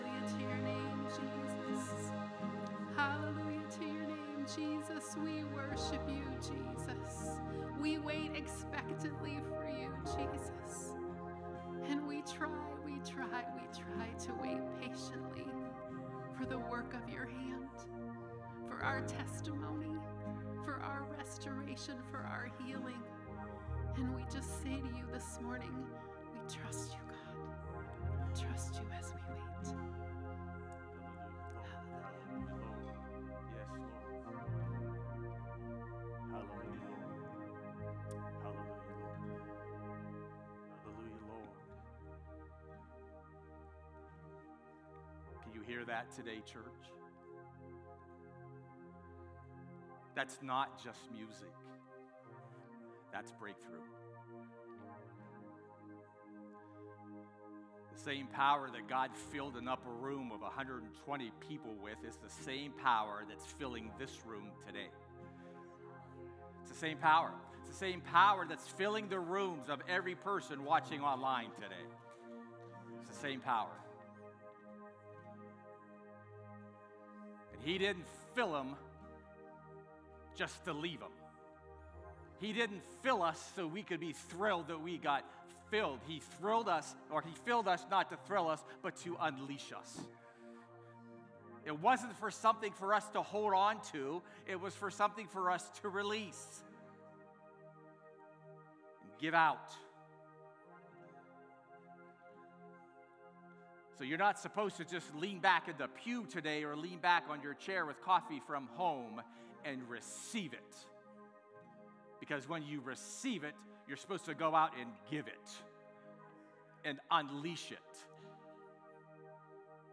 “Let Loose” is a bold, Spirit-led sermon series that calls believers to move beyond revival and into release. After being recharged and reignited by God’s power, it’s time to shake off old limitations, step out of spiritual graves, and let loose the fire, gifts, and purpose God has awakened within us.